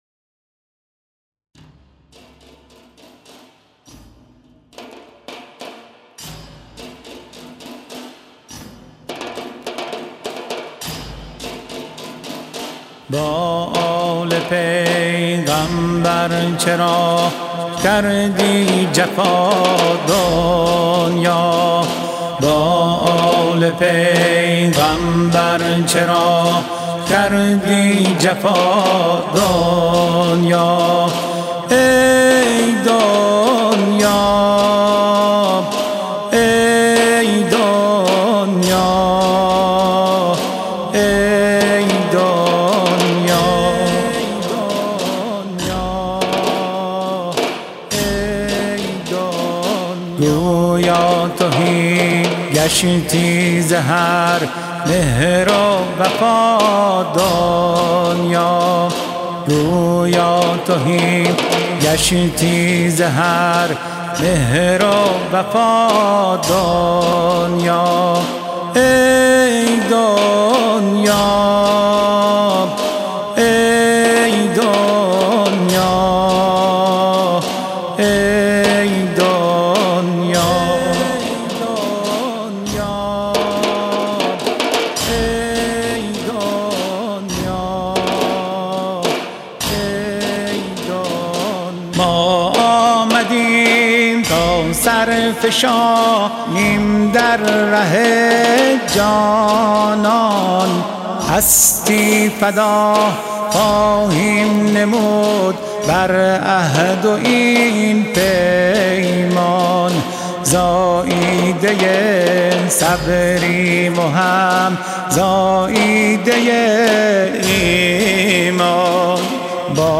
زنجیرزنی